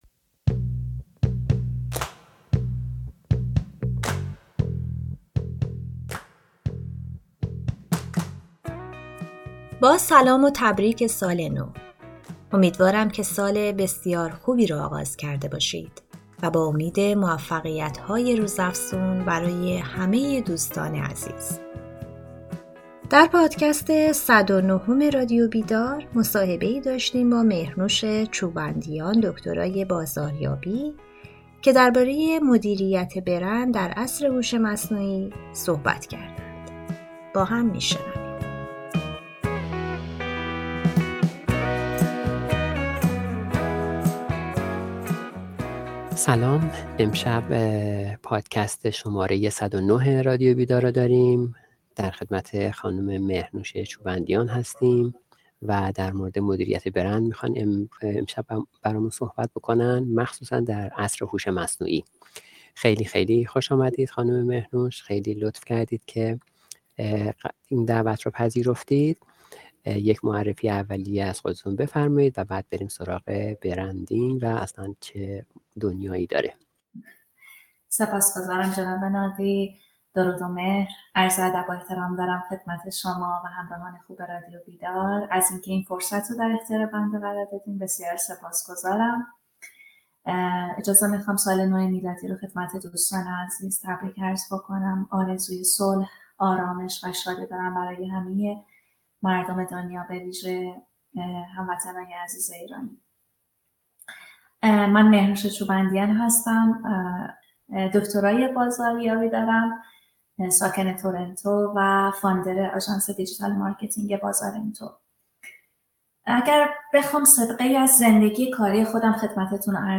درباره مدیریت برند در عصر هوش مصنوعی گفتگو کردیم.